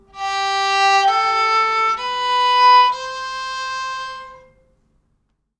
Sounds on Kamancheh strings on the assumption of basing the most prevalent tune (TUNE 1 in these instruments) are like this:
2nd string in 1st position: